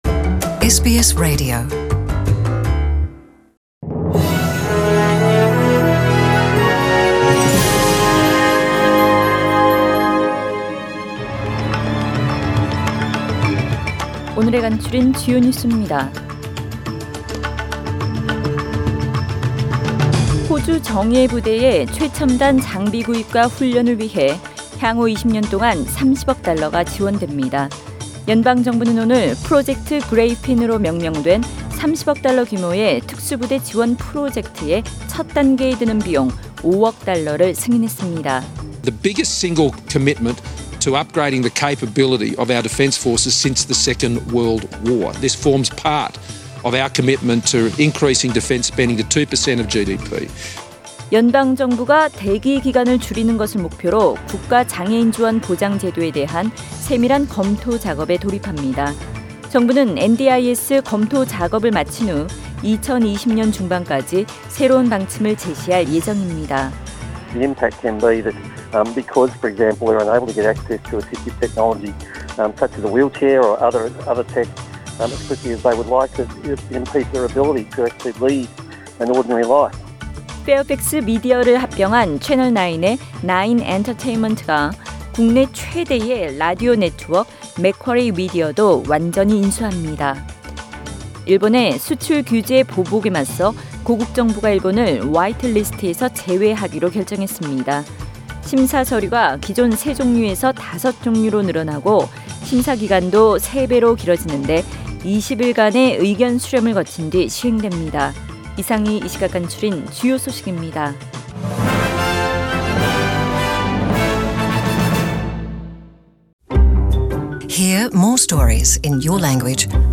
SBS 한국어 뉴스 간추린 주요 소식 – 8월 12일 월요일
2019년 8월 12일 월요일 저녁의 SBS Radio 한국어 뉴스 간추린 주요 소식을 팟 캐스트를 통해 접하시기 바랍니다.